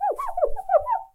cleaner_pencil_erase.ogg